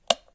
switch17.ogg